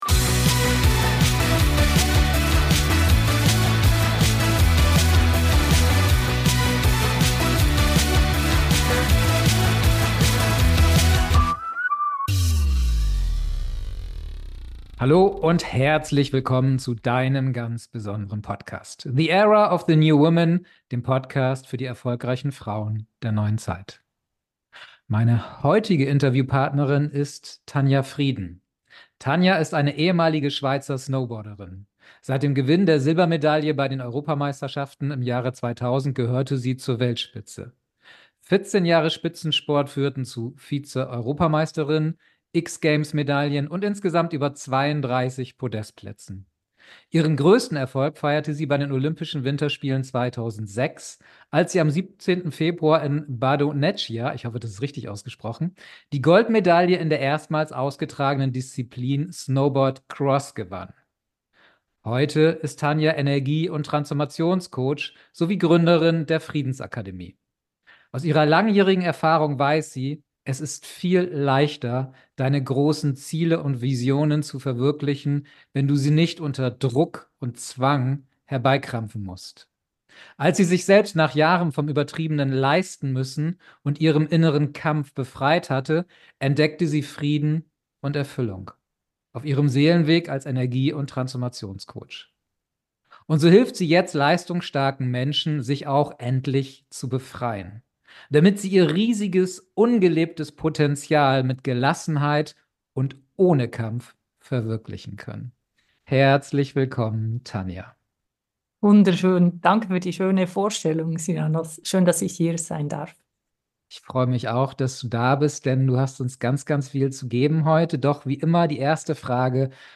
#032 Vom Gold bei Olympia zum Gold im Leben. Das Interview mit Tanja Frieden.